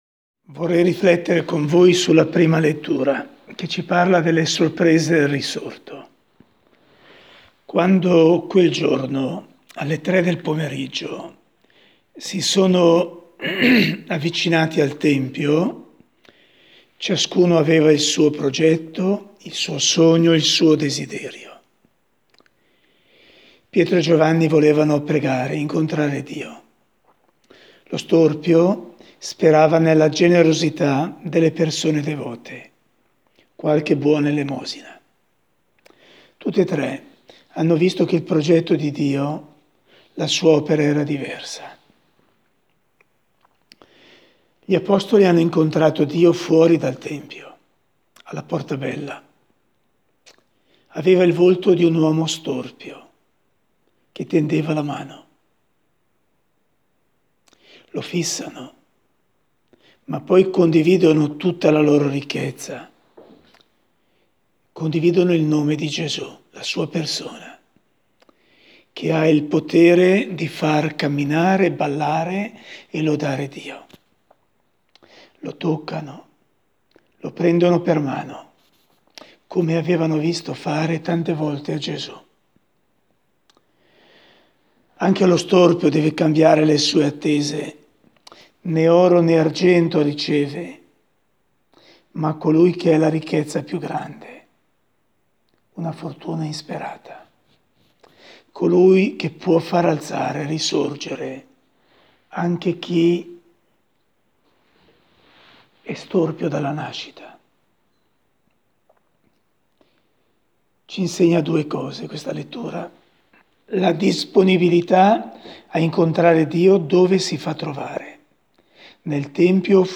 Le nostre novità Il commento alle letture del 15 aprile Commento alle letture di oggi ASCOLTA QUI Lettura 15 aprile 2020.m4a 15/04/2020 Attività Adulti Liturgia Cultura - formazione < Torna all'elenco